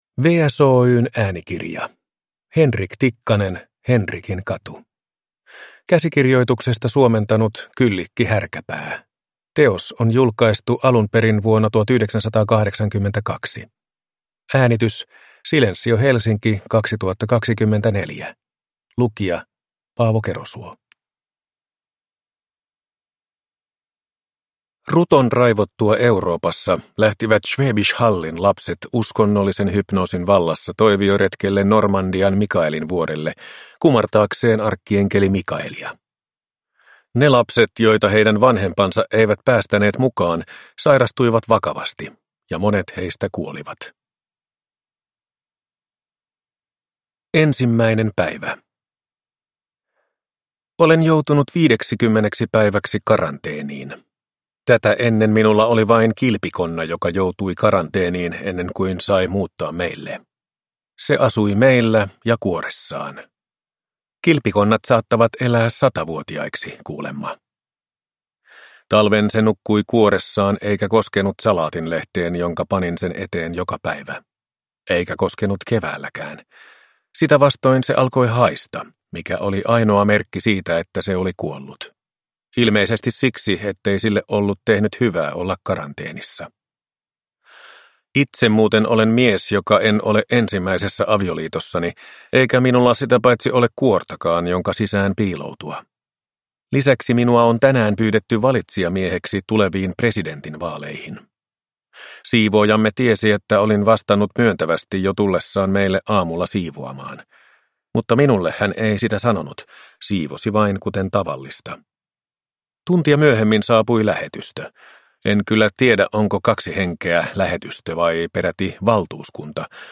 Henrikinkatu – Ljudbok